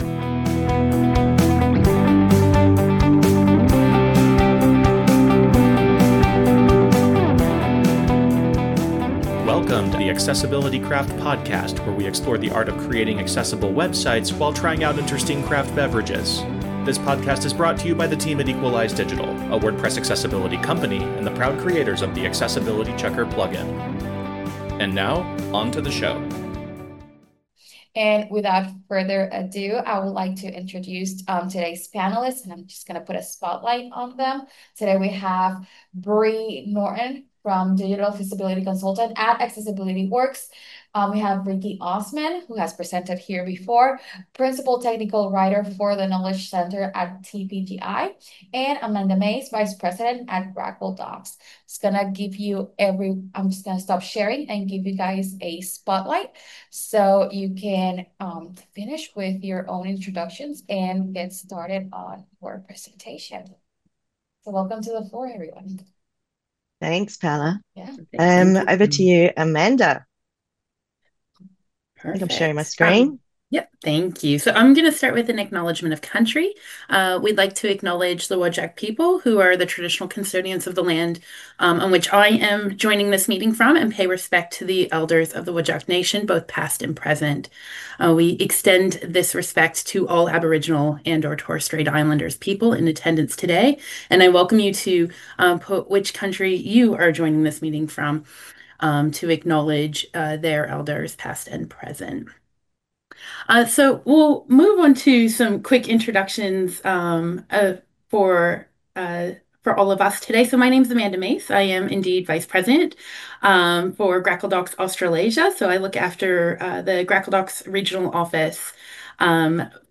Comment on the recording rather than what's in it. This episode is a recording of a June 2025 WordPress Accessibility Meetup